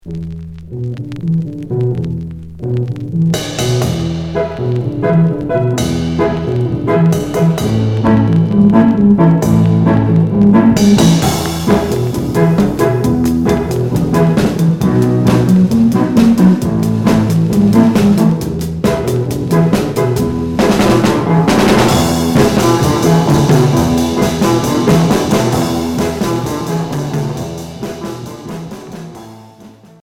Rock 60's Unique EP